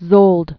(zōld), Henrietta 1860-1945.